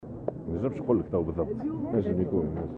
وأضاف الشاهد في تصريح إعلامي اليوم الخميس على هامش انطلاق أشغال المنتدى الاقتصادي التونسي الليبي بتونس : " التحوير الوزاري ينجم يكون ولا يمكن الحديث الآن عن موعده."